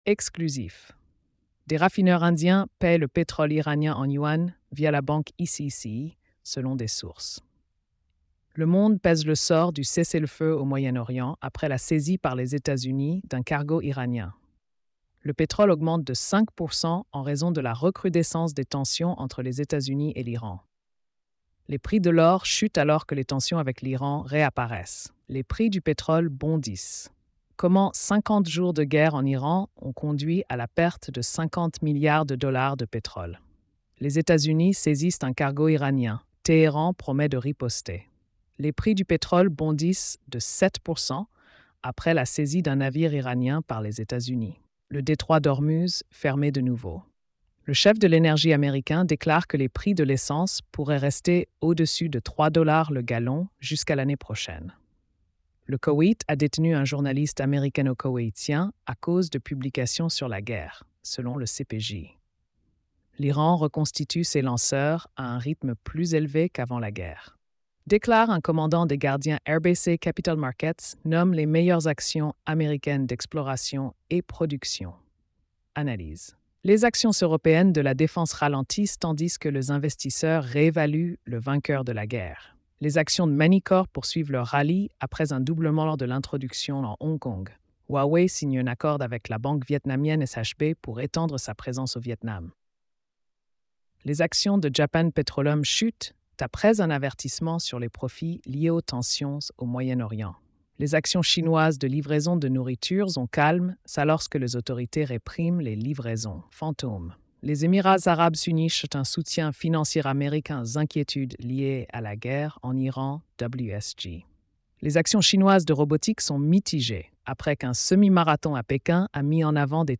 🎧 Résumé économique et financier.